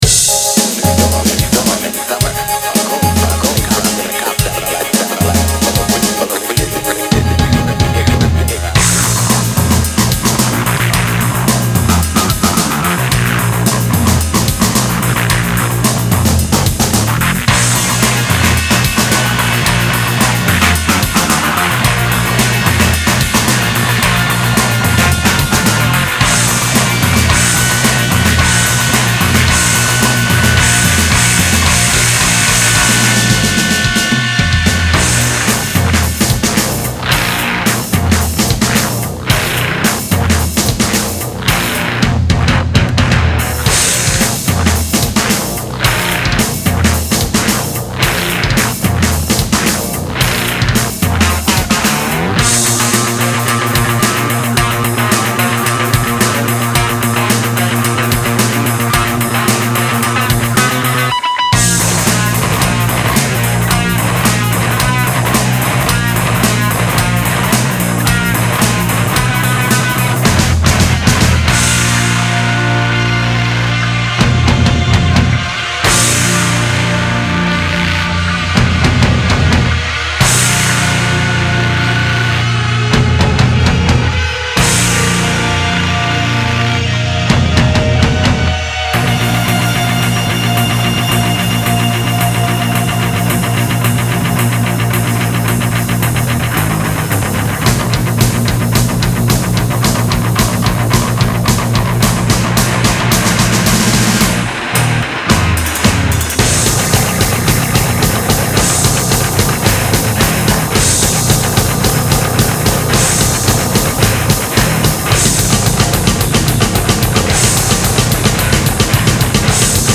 BPM55-110
Audio QualityPerfect (High Quality)
Several One vocal samples can be heard.